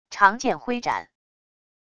长剑挥斩wav音频